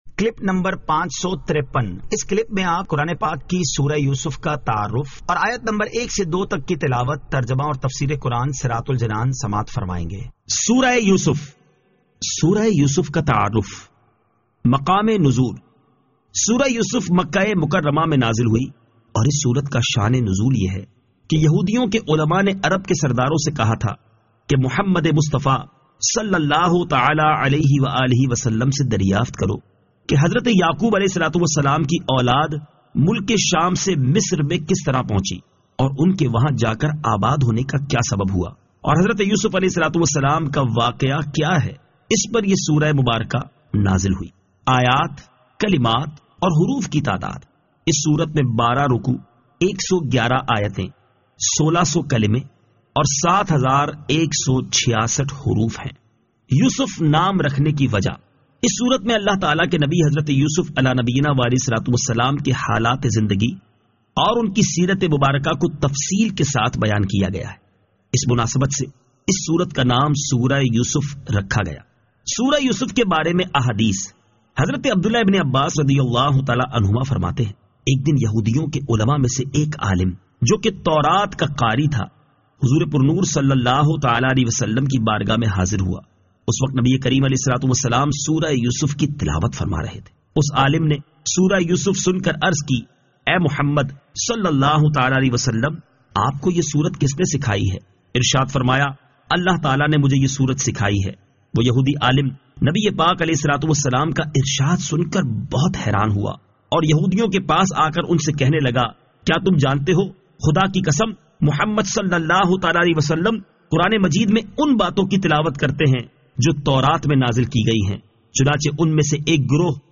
Surah Yusuf Ayat 01 To 02 Tilawat , Tarjama , Tafseer